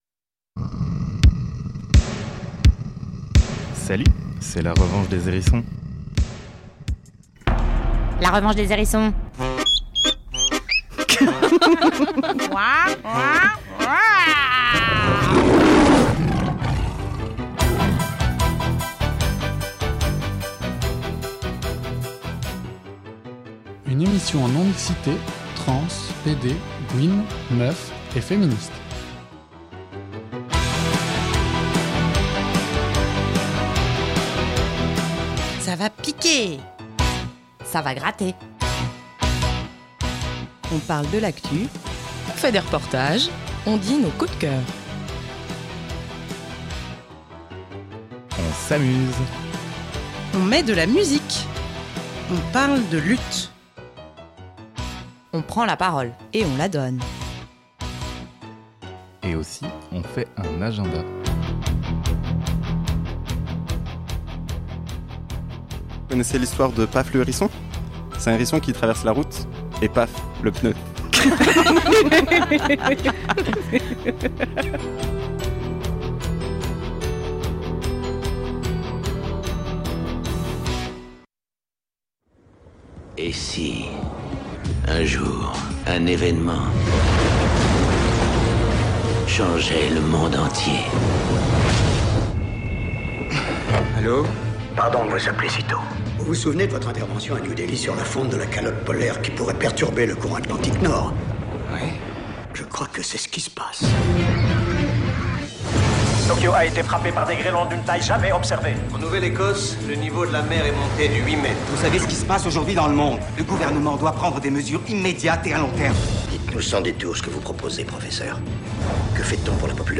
La Revanche des Zhérissons est une émission réalisée par des tranpédégouines et des meufs, dans une perspective féministe.
Nous sommes diffuséEs sur JET les 1er et 3ème lundis du mois entre 17h et 18h, et en podcast sur le site de JET.